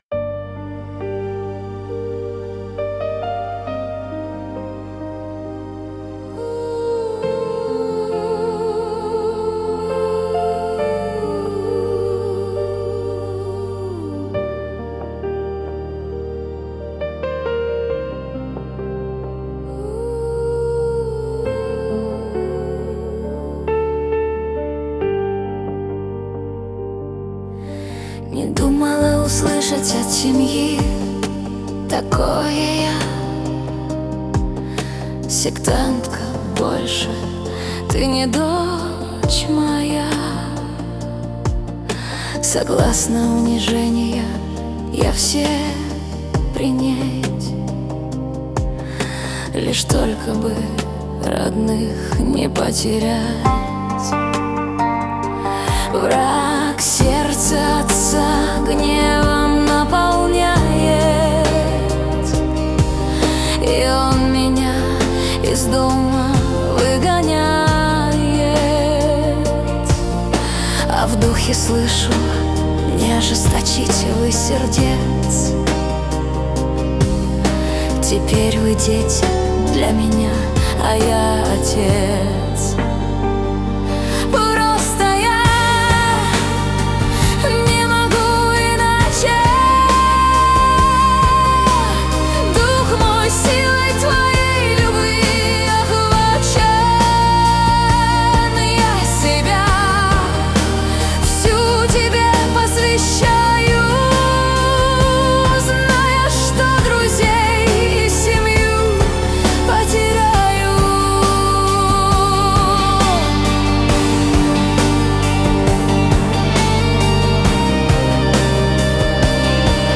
песня ai
191 просмотр 663 прослушивания 79 скачиваний BPM: 68